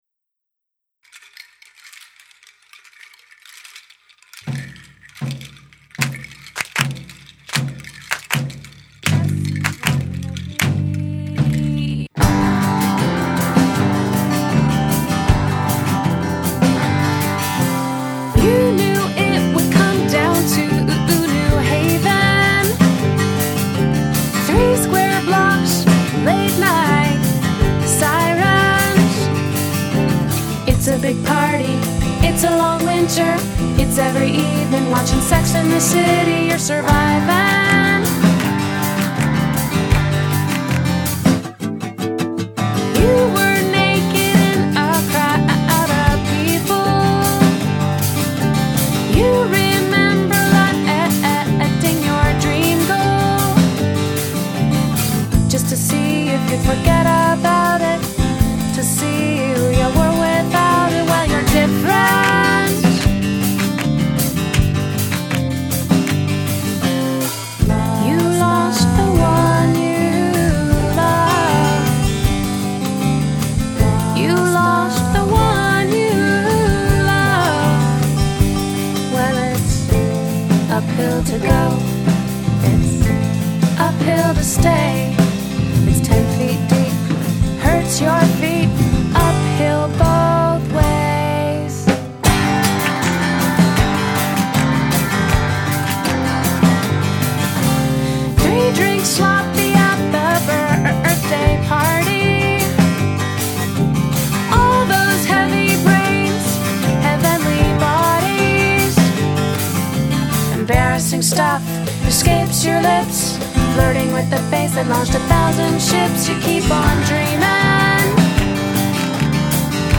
Lyricist, guitarist, singer and poet